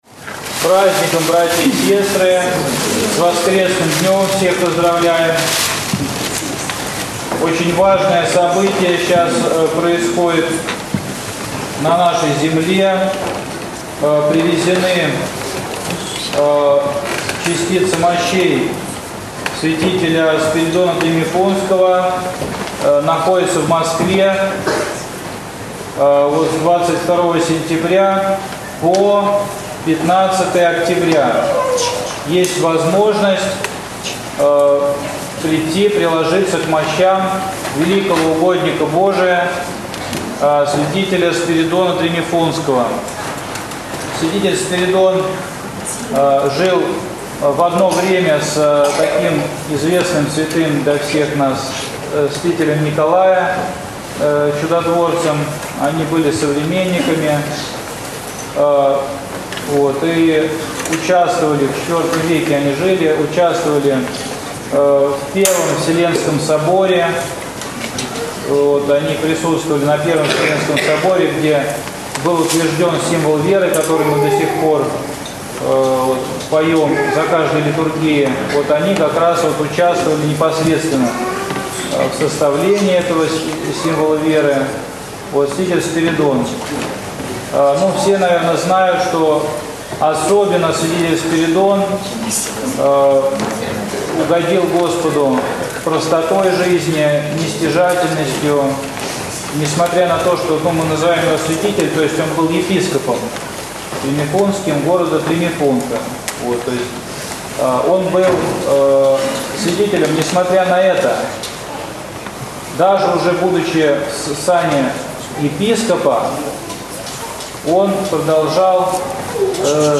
Неделя 17-я по Пятидесятнице. Проповедь